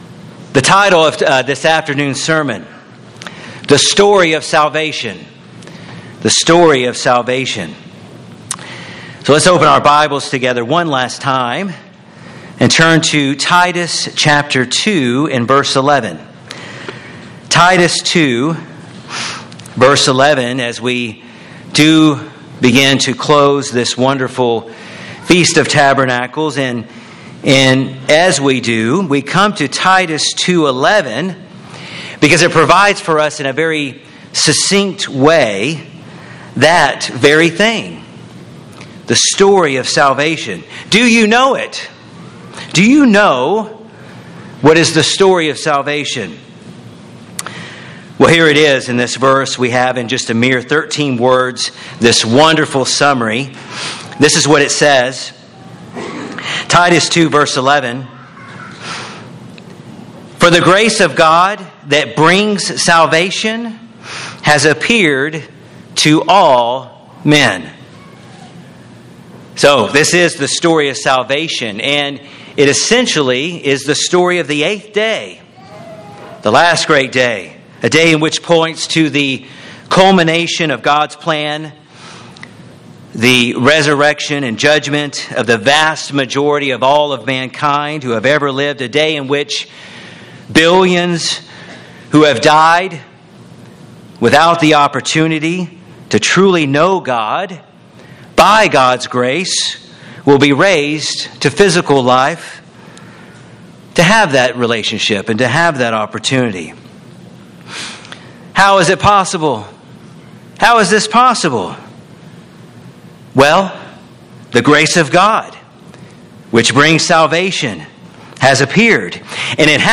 This sermon was given at the Lake Junaluska, North Carolina 2019 Feast site.